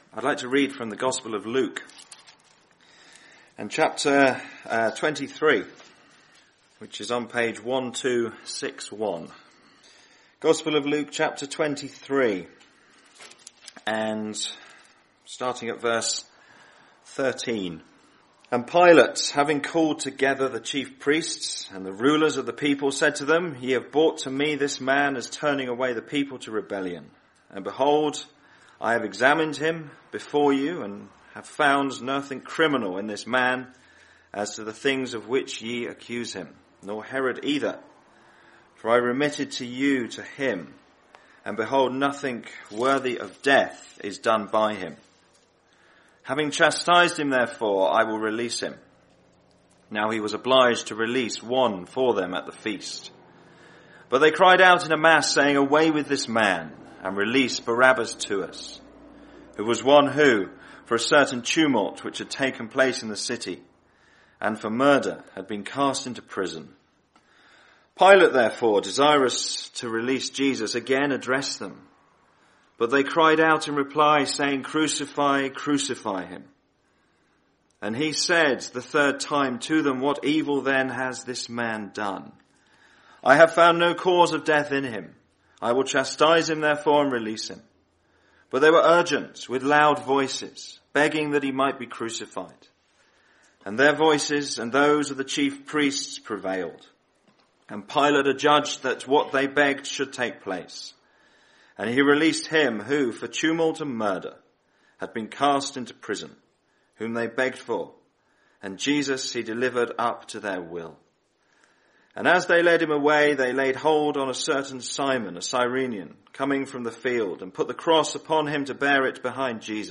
In this preaching, you will hear about the centre piece of the Gospel message, the crucifixion of Jesus Christ. Over 2000 years ago, Jesus journeyed to Calvary's Cross to redeem us from our sins.